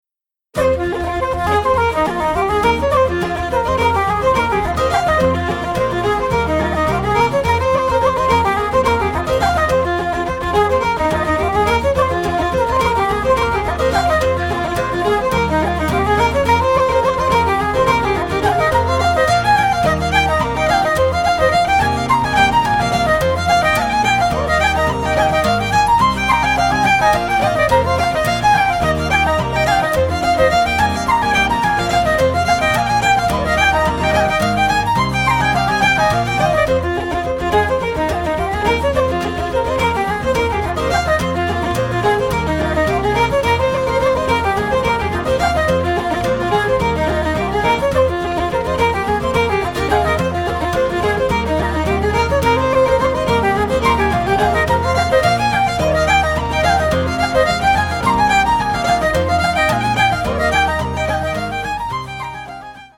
Fiddle
Flute
Guitars
Bouzouki and Bodhran